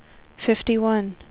WindowsXP / enduser / speech / tts / prompts / voices / sw / pcm8k / number_71.wav
number_71.wav